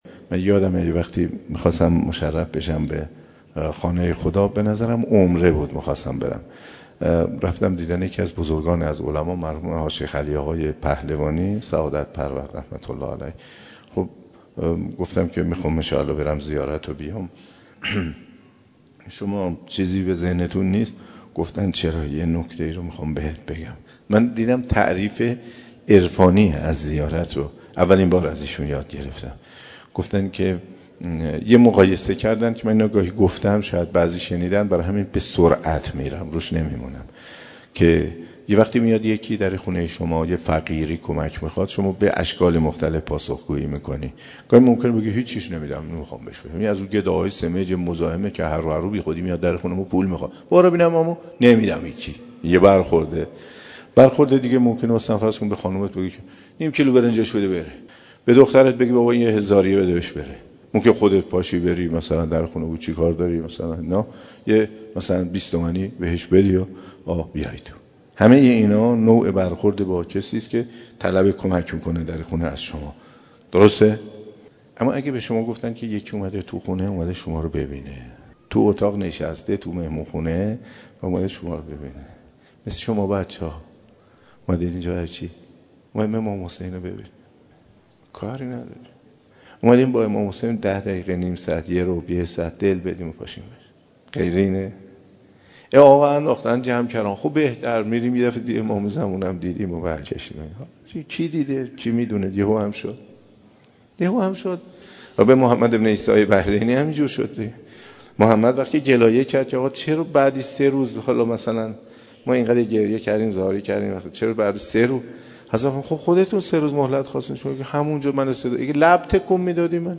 صوت سخنرانی حجت الاسلام و المسلمین مرتضی آقا تهرانی درباره تعریف عرفانی زیارت منتشر می شود.